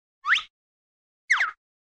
Звуки подмигивания